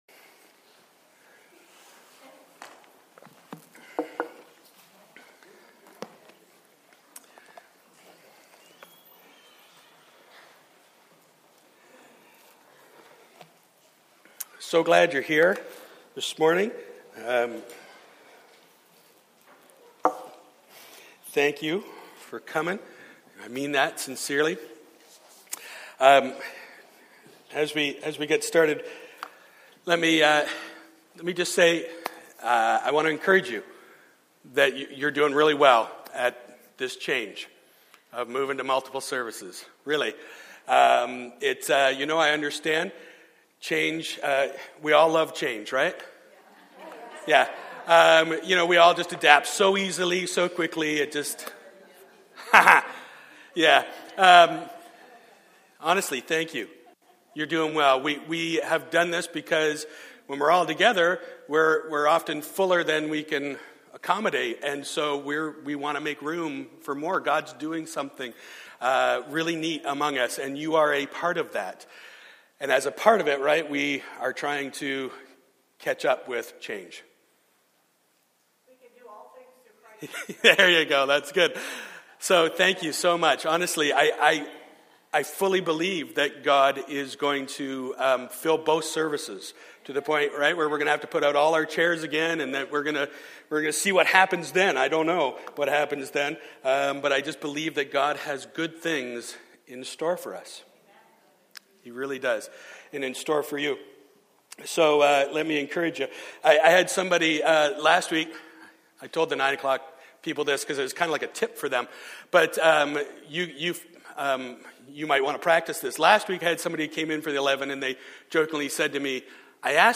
Sermons | Christian Life Fellowship